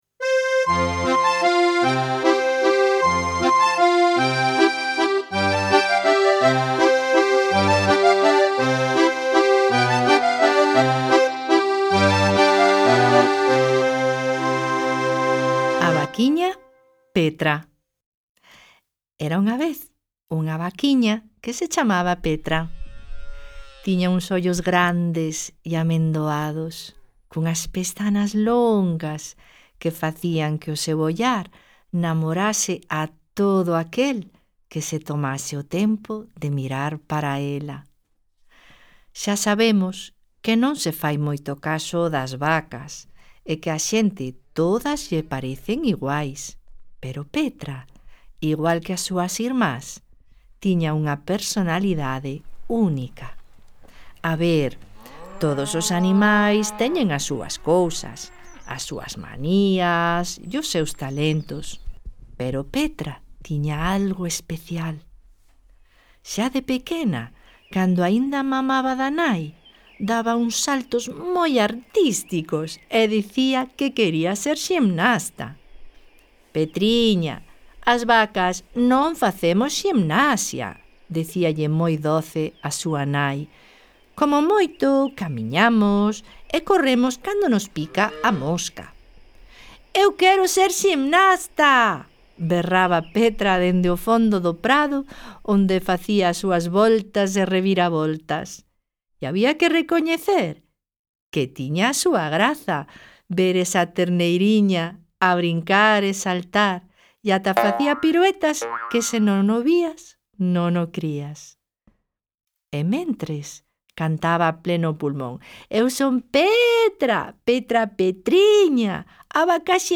Audioconto: A vaquiña Petra | Contiños para despertar versos
A_vaca_Petra_audioconto_con_efectos.mp3